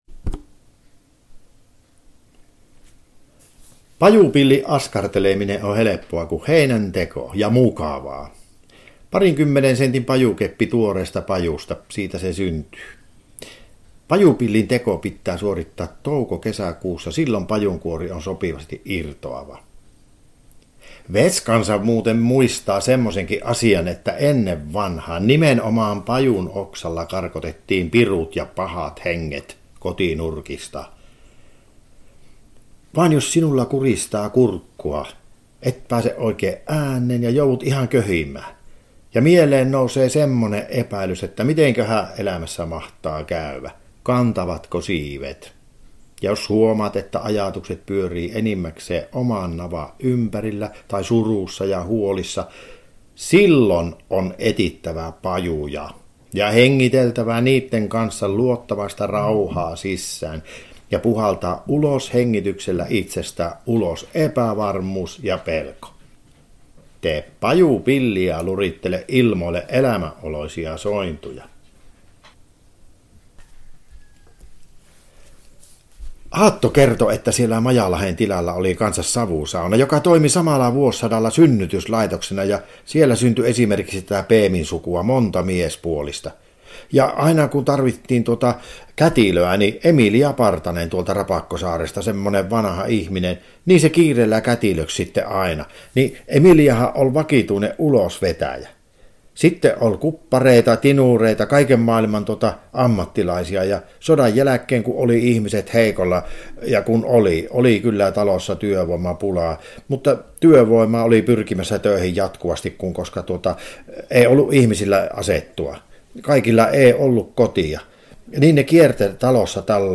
Puhujana näyttelijä